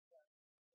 在线英语听力室【荆棘鸟】第七章 08的听力文件下载,荆棘鸟—双语有声读物—听力教程—英语听力—在线英语听力室